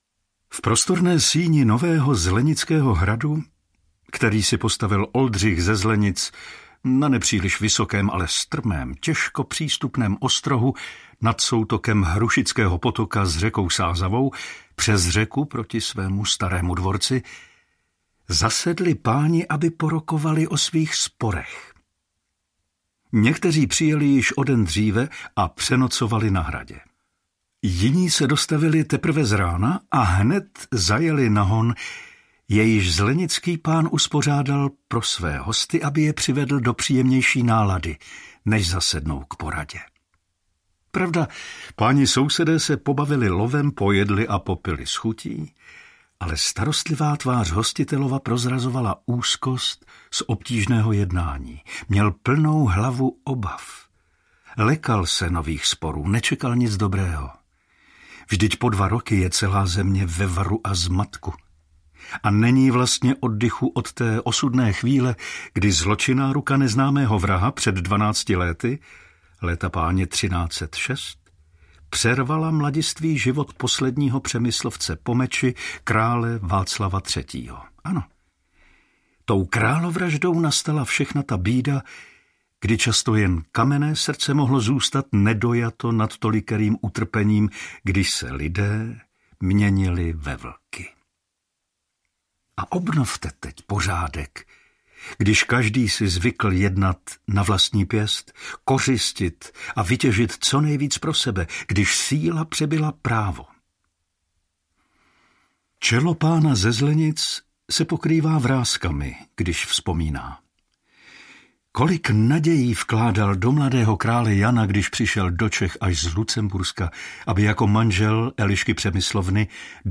Zločin na Zlenicích hradě audiokniha
Ukázka z knihy